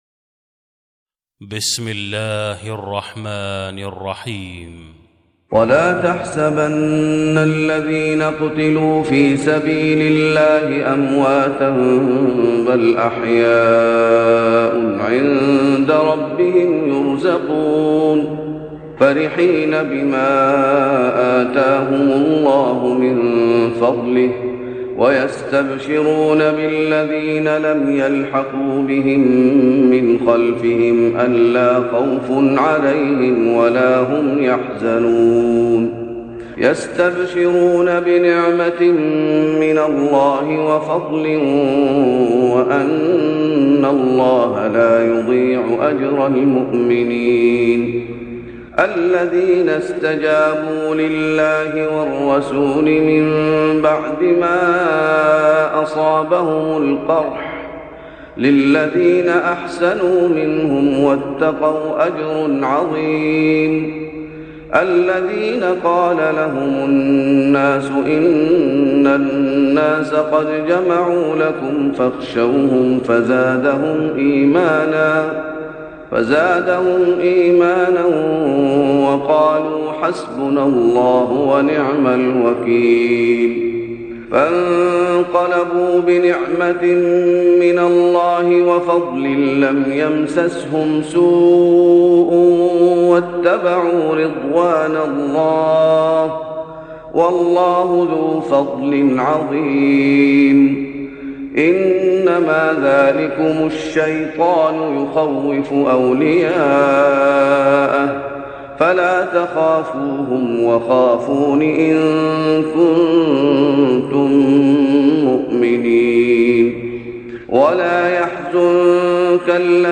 تهجد رمضان 1416هـ من سورة آل عمران (169-200) Tahajjud Ramadan 1416H from Surah Aal-i-Imraan > تراويح الشيخ محمد أيوب بالنبوي 1416 🕌 > التراويح - تلاوات الحرمين